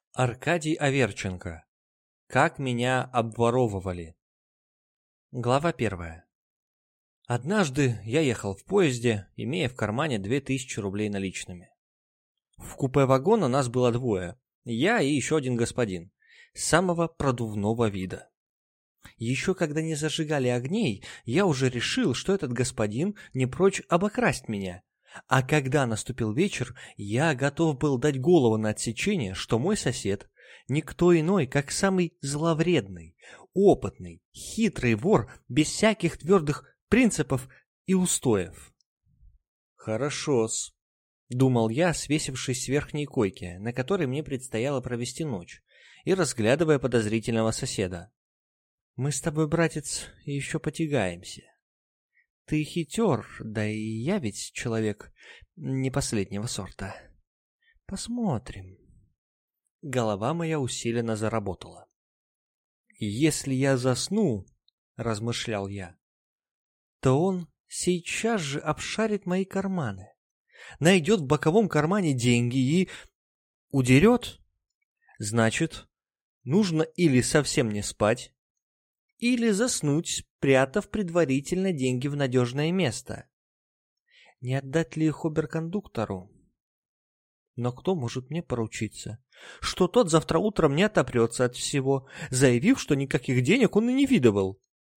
Аудиокнига Как меня обворовывали | Библиотека аудиокниг